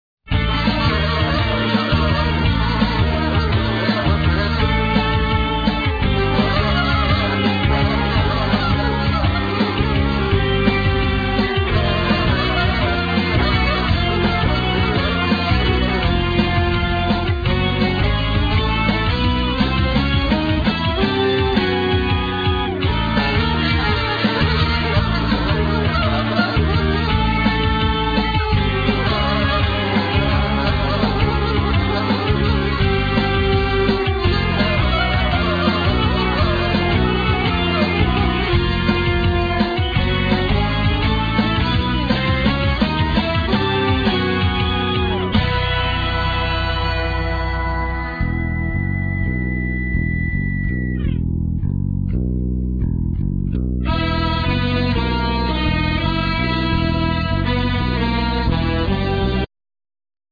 Vocal,Saxophne,Guitar solo,Synthsizer
Guitars,Synthsizer
Bass
Drums
Violin